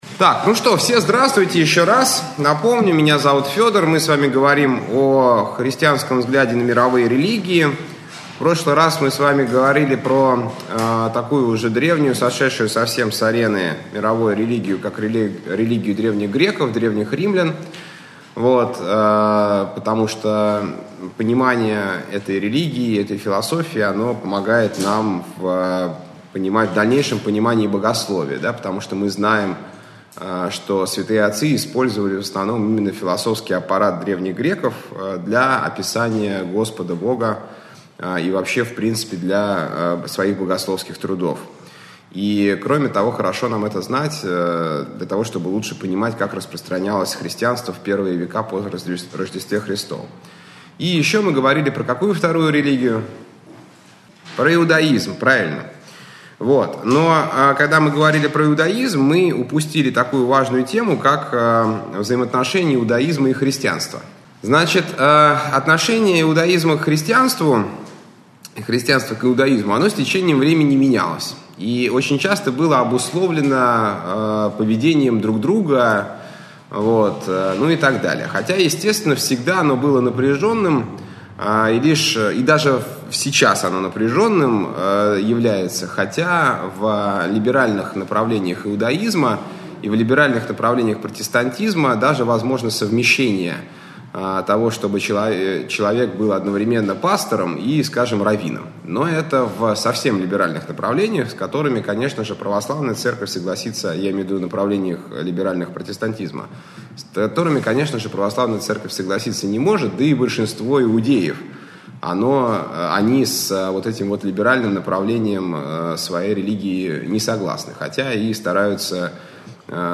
Общедоступный православный лекторий 2013-2014